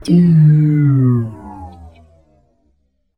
Robot Shutdown
android artificial automation bionic command computer cyborg droid sound effect free sound royalty free Memes